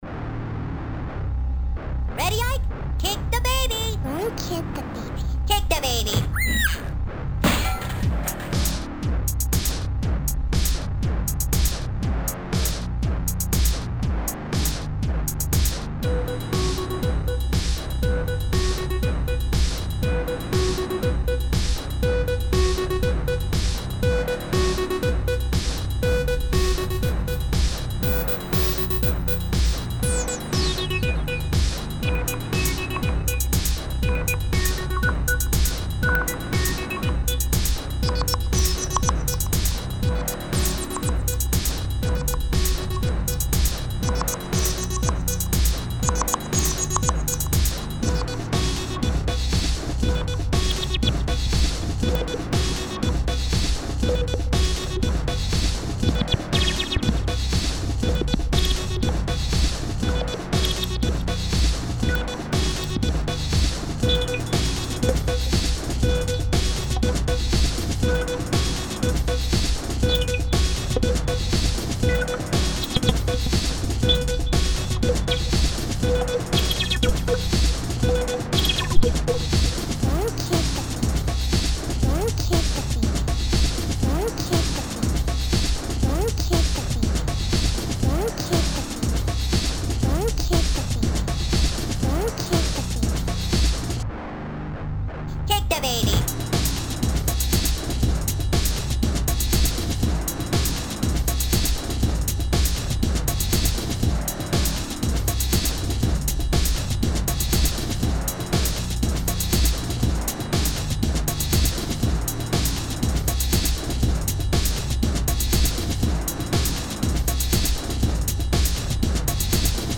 Metal was creeping back into my musicality.